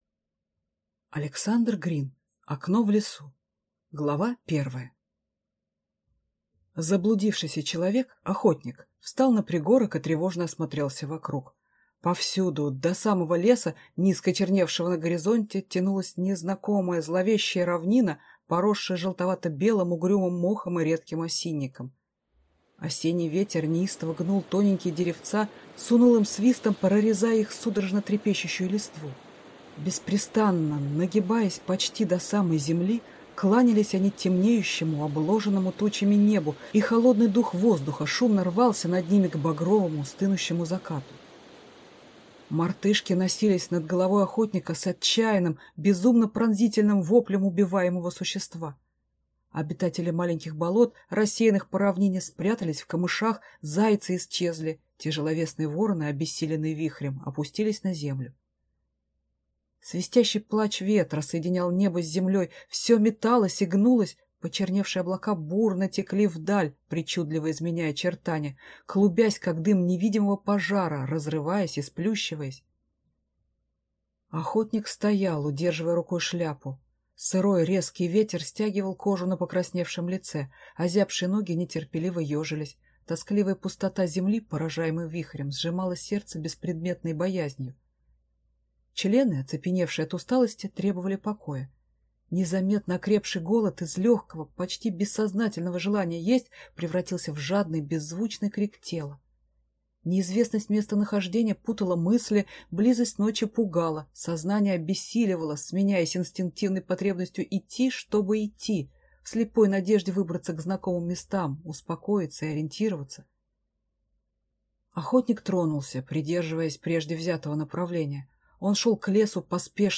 Аудиокнига Окно в лесу | Библиотека аудиокниг